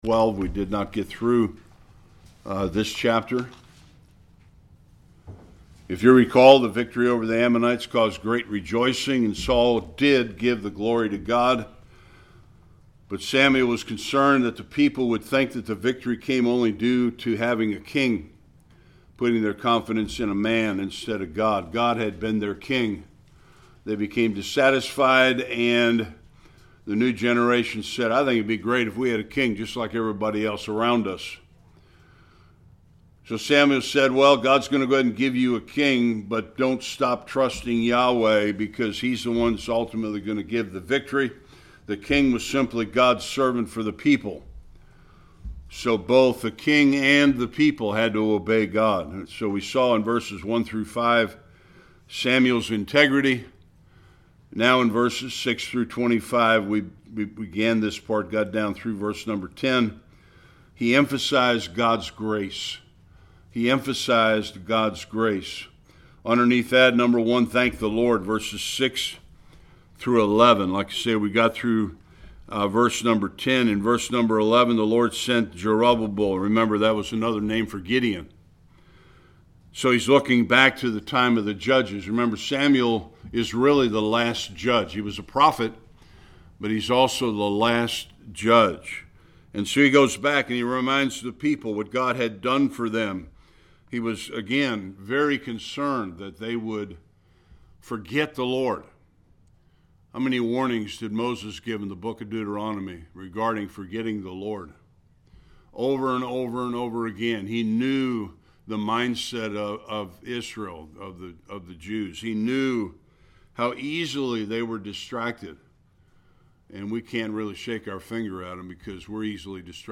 9-25 Service Type: Sunday School Samuel emphasizes God’s grace.